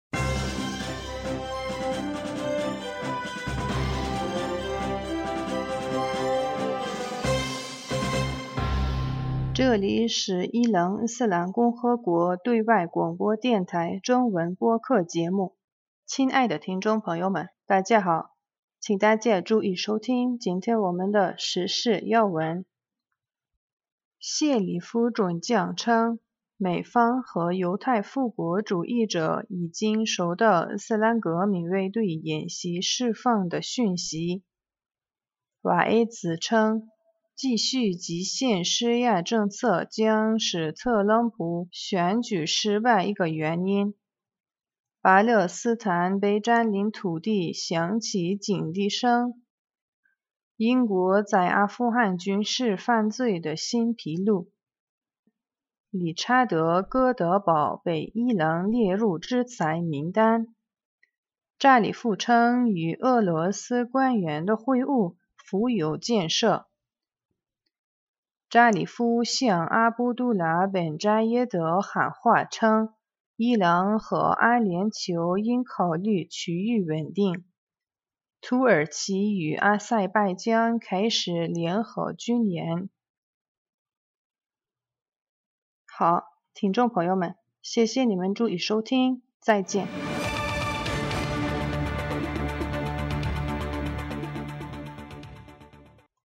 2020年8月3日 新闻